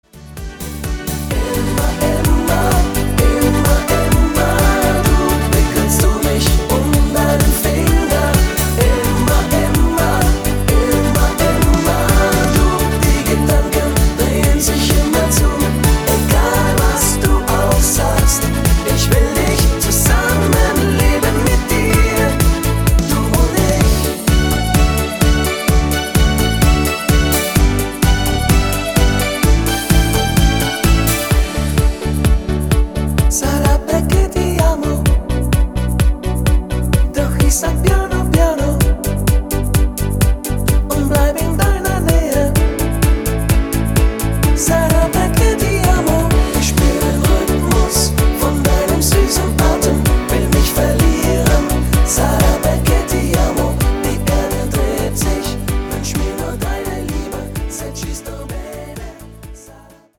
Rhythmus  Discofox
Art  Deutsch, Medleys, ML Remix
ETS (easy to sing) = einfacher und
leichter zu singende Tonarten